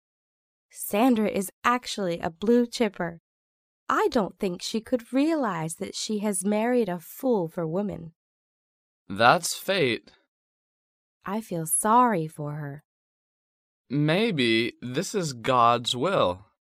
英语情景对话：